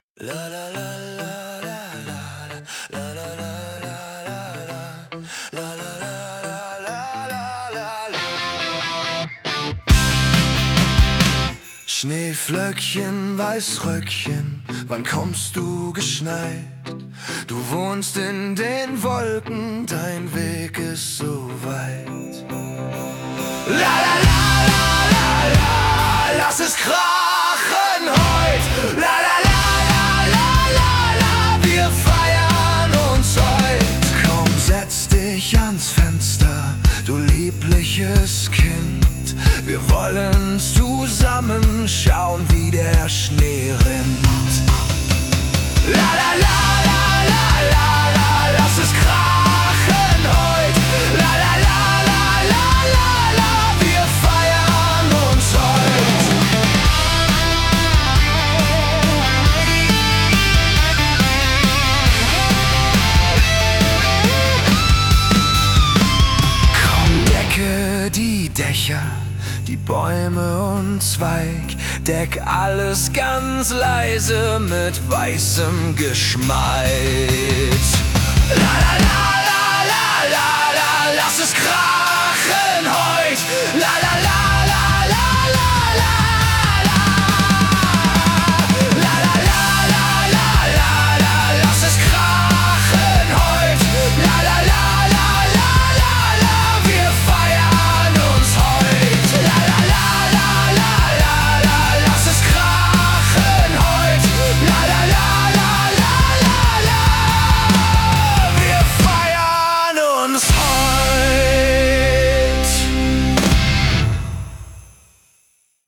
Party-Rock-Power – Heute schneit’s richtig laut!
Jetzt mit 100 % mehr Party, 0 % Kitsch.